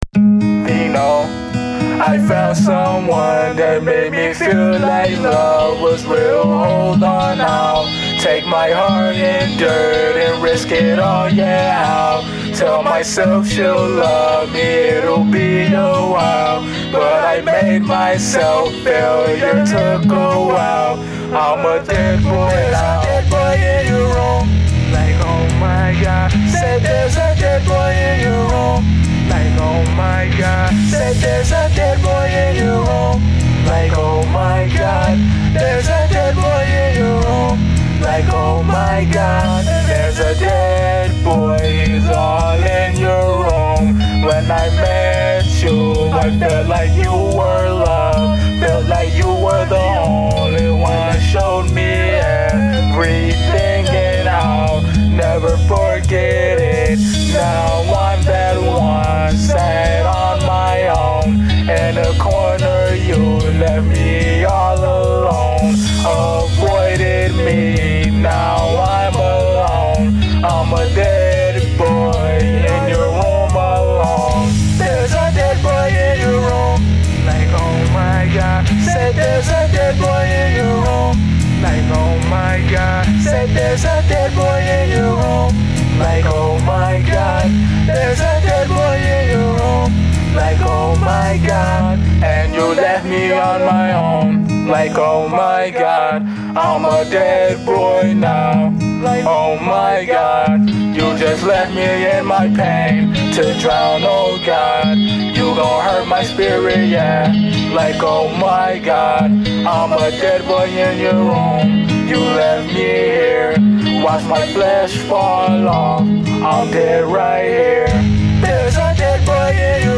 ALETERNATIVE ROCK & INDIE ROCK